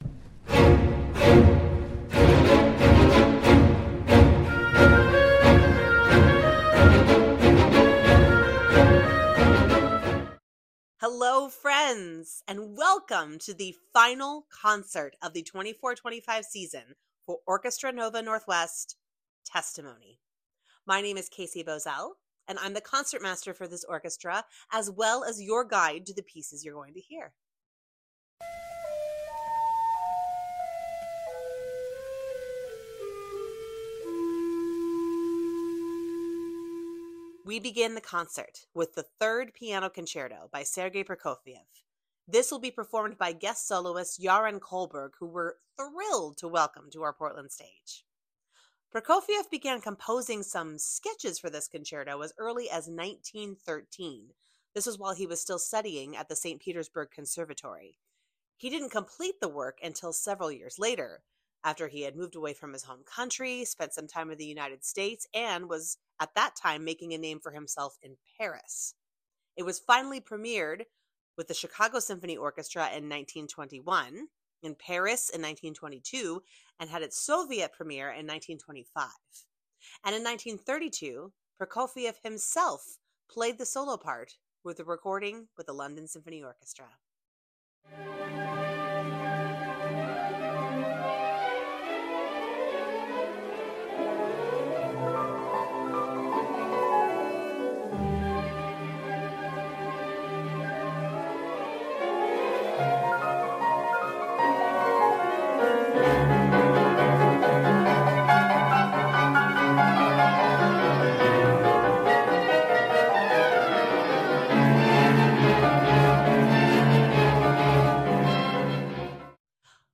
Testimony Pre-Concert Talk | Orchestra Nova Northwest